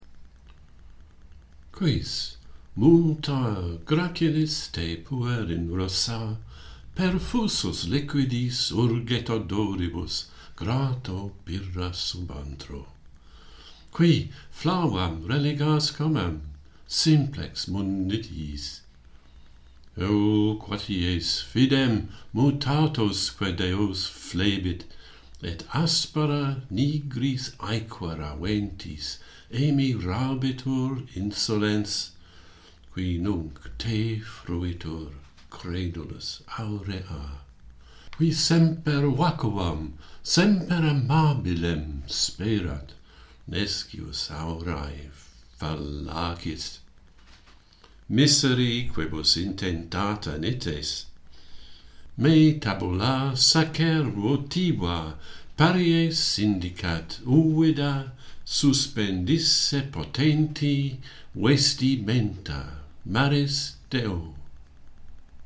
Pyrrha - Pantheon Poets | Latin Poetry Recited and Translated
Metre: fourth Asclepiad